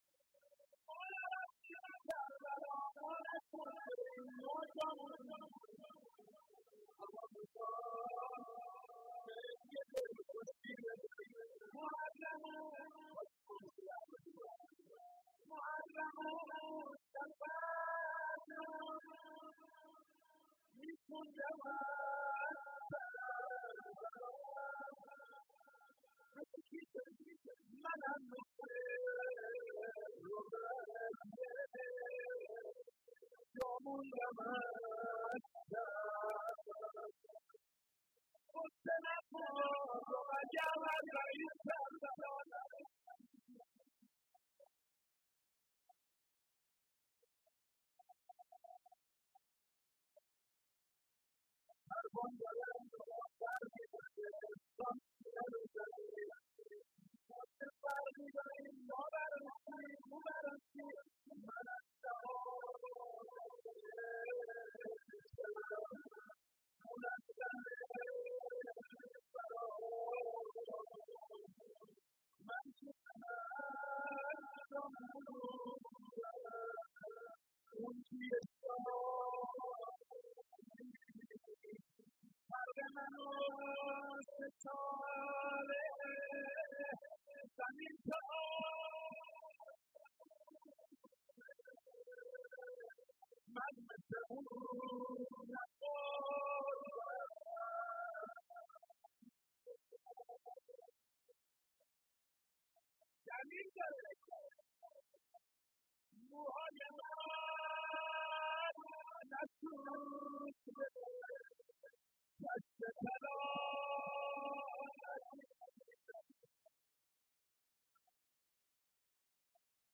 روضه
روضه و ذکر